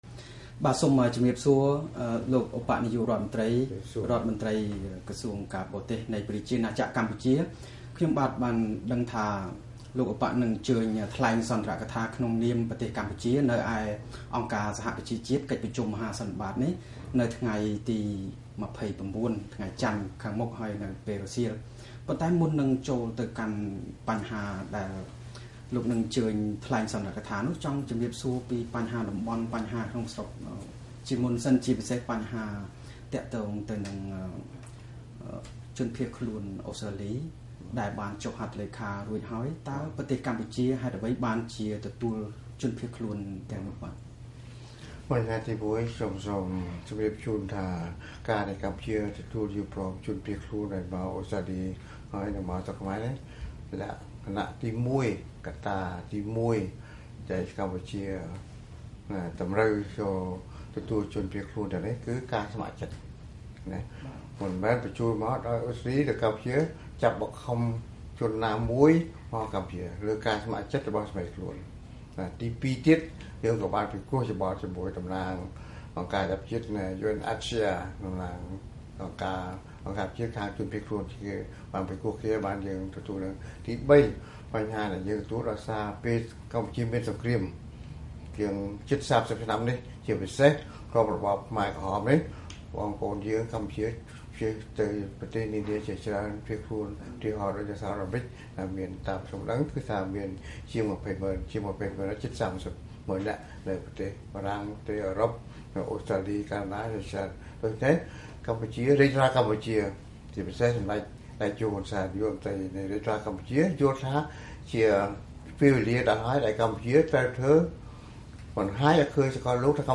បទសម្ភាសន៍វីអូអេជាមួយលោក ហោ ណាំហុង អំពីបញ្ហាអន្តរជាតិនៅអ.ស.ប.